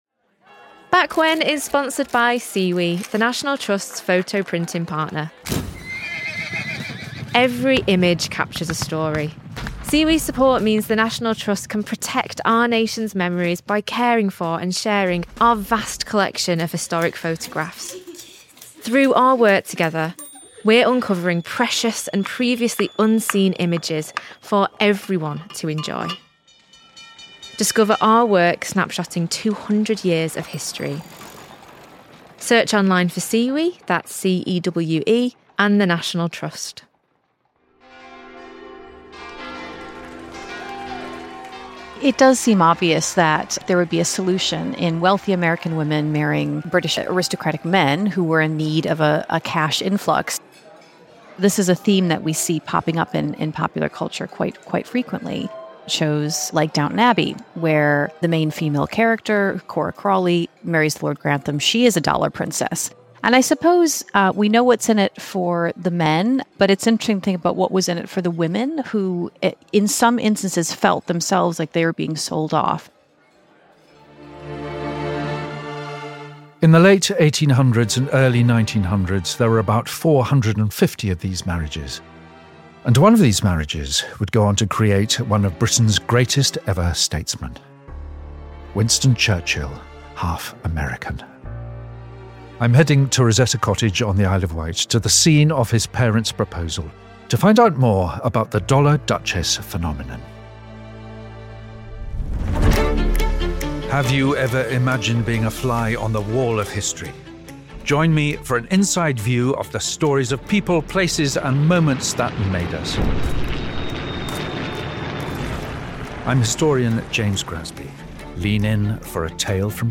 Isle of Wight local tour guides Recording took place at Rosetta Cottage on the Isle of Wight where Churchill’s parents got engaged.